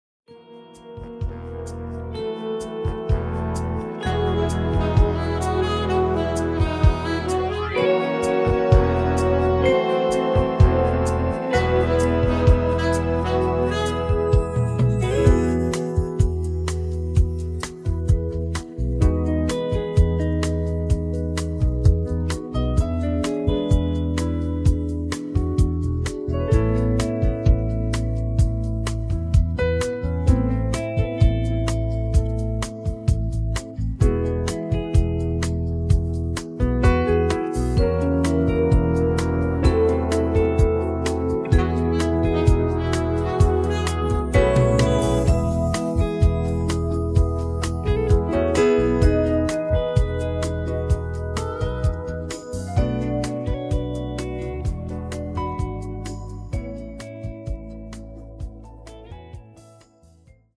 음정 원키 3:17
장르 가요 구분 Voice Cut